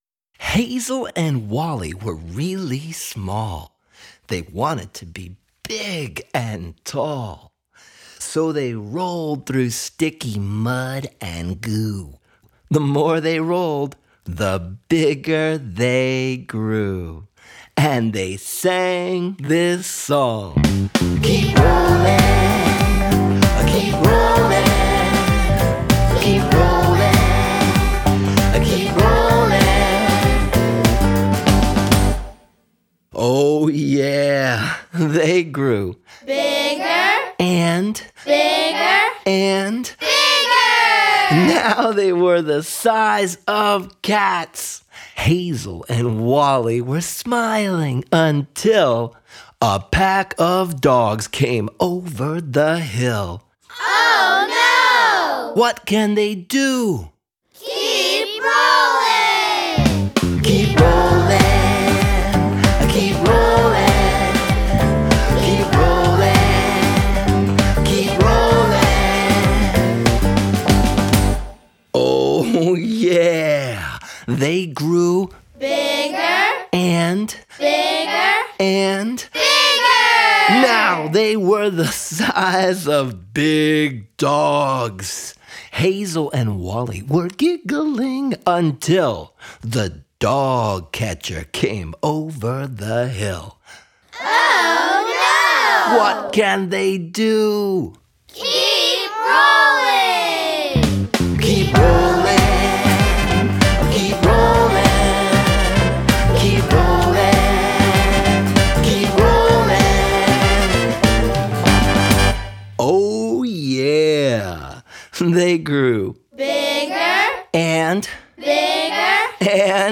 The Nuts: Keep Rolling Story Read-aloud!